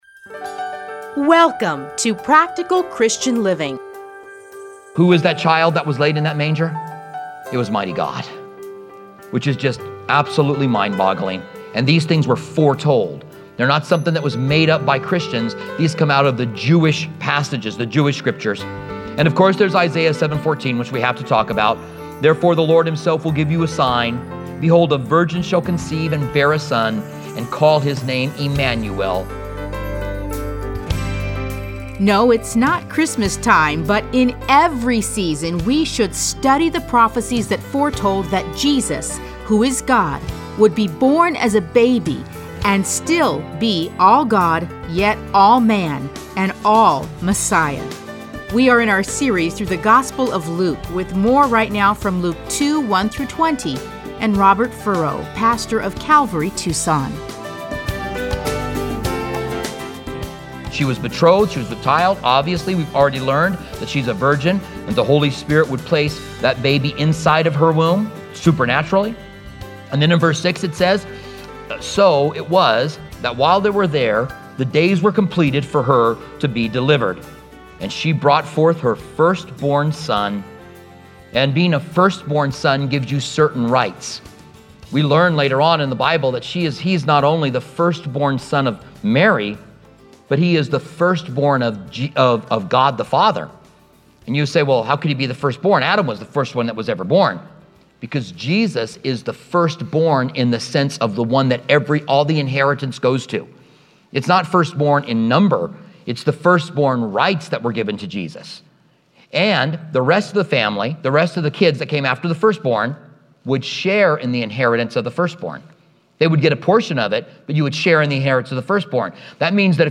Listen to a teaching from Luke 2:1-20.